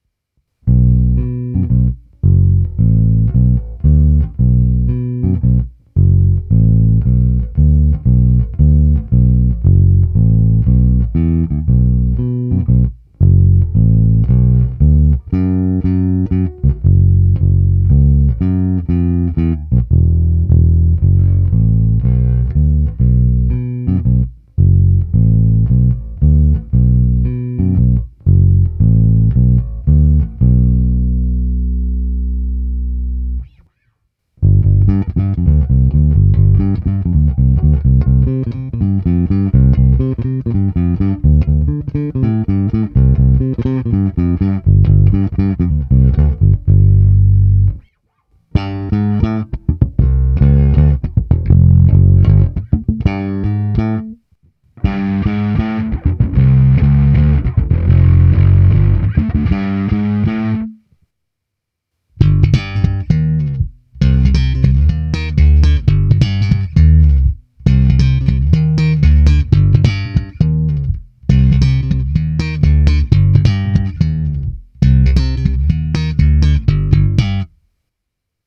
Masívní basy, vrčivé středy, kousavé výšky.
Nahrávka přes předzesilovač Darkglass Alpha Omega Ultra se zapnutou simulací aparátu a také přes kompresor TC Electronic SpectraComp v pořadí povinná nahrávka, bonusová nahrávka, ukázka zkreslení a nakonec slap.